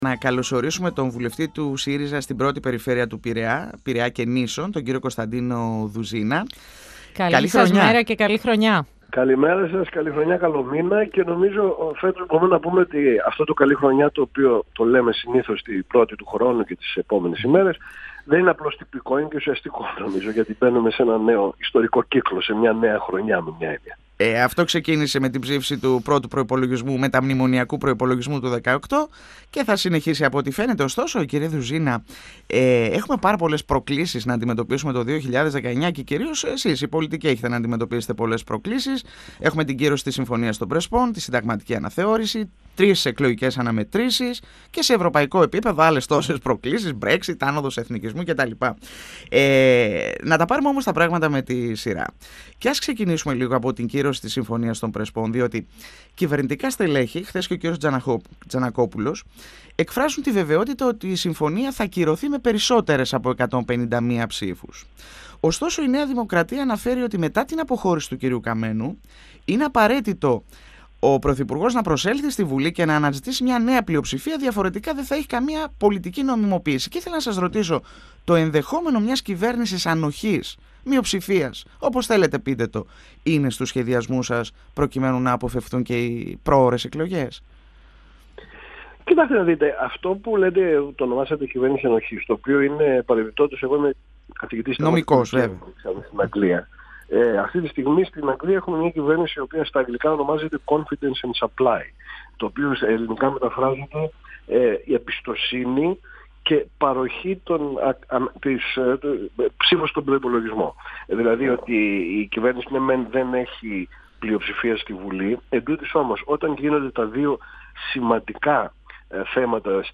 Τη βεβαιότητα ότι θα υπάρξει «μια καλή πλειοψηφία» όταν η Συμφωνία των Πρεσπών εισαχθεί στο ελληνικό κοινοβούλιο εξέφρασε ο βουλευτής Α΄ Πειραιά και Νήσων του ΣΥΡΙΖΑ Κωνσταντίνος Δουζίνας μιλώντας στον 102 fm της ΕΡΤ3.